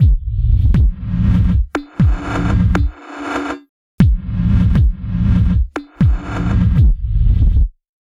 Abstract Rhythm 43.wav